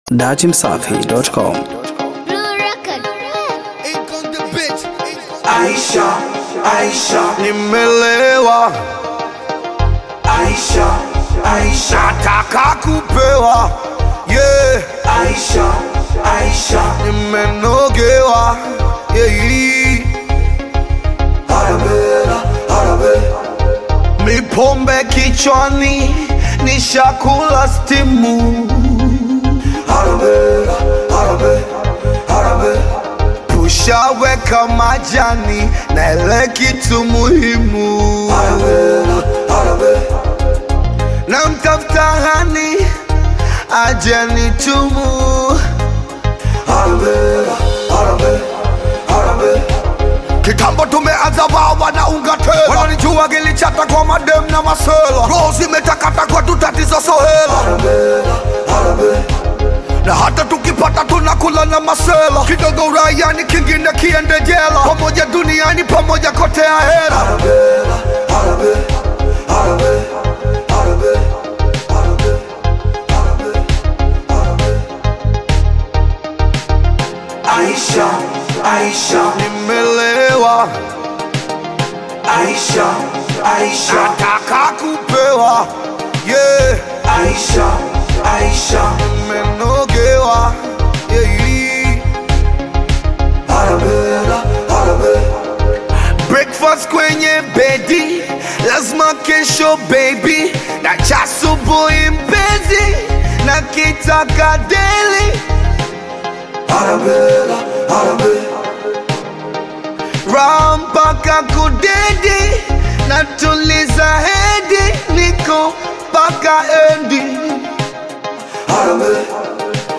Bongo Flavour Singeli